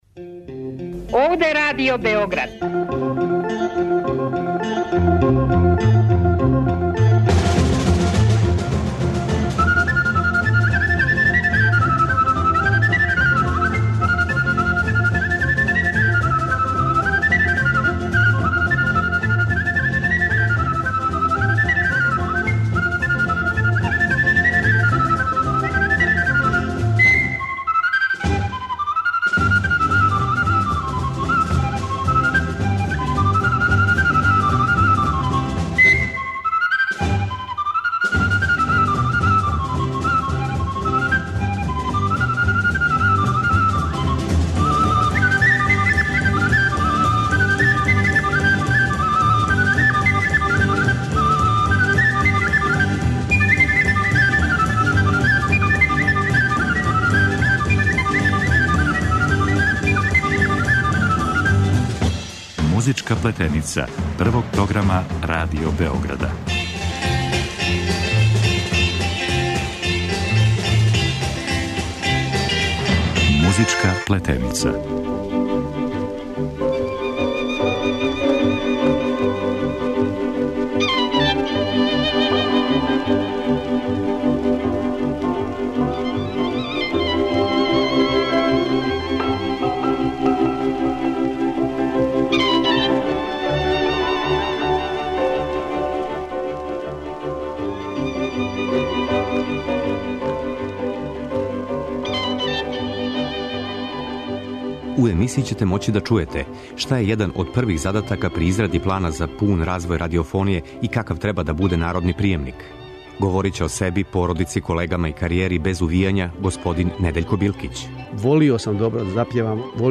Музичка плетеница је емисија о народној музици, њеним посленицима и актерима, посредним и непосредним, па ако вас ова тема интересује, будите на таласима Радио Београда 1 у недељу 11. септембра од 14 часова.